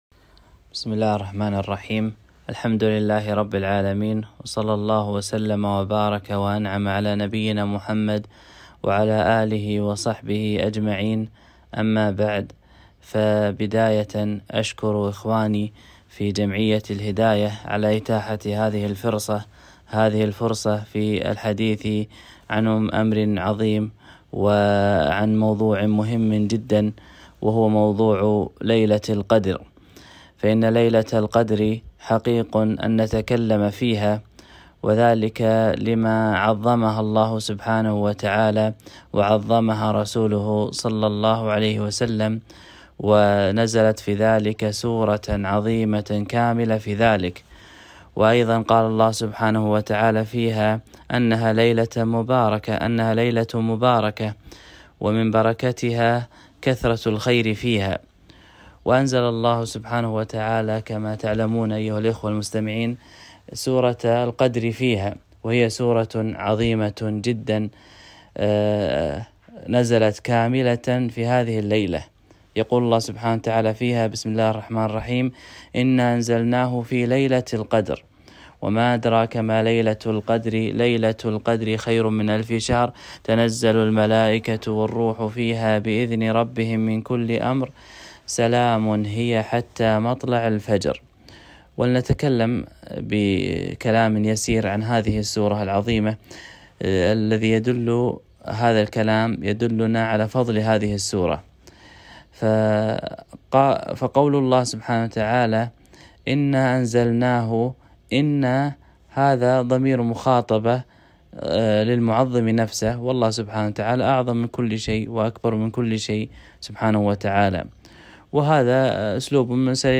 محاضرة - ليلة خير من ألف شهر